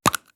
Abrir la caja de un carrete de fotografías